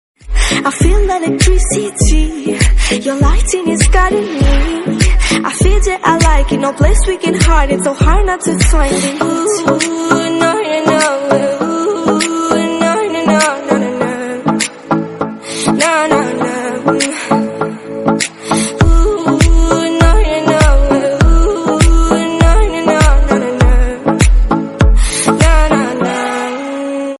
Sad English Ringtone